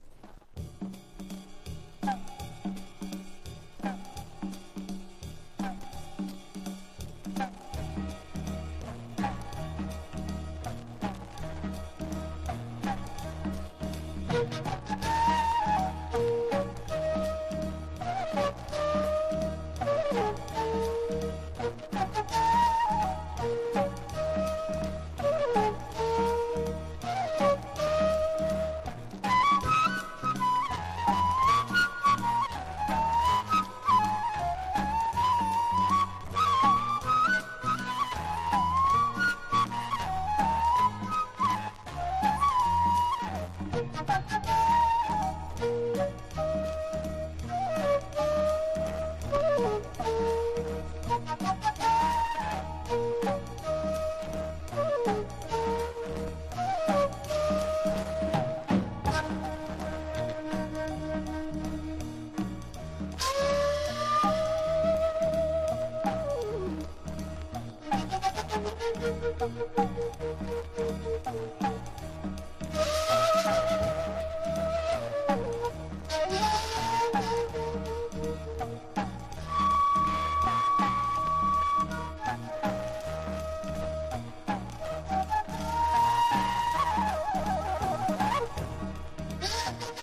琵琶や琴などの和楽器をフューチャーし、美しく幻想的な響きとグルーヴがみなぎるオリエンタル・ジャズ&ファンク神秘の1枚！
# 和ジャズ# 和モノブレイク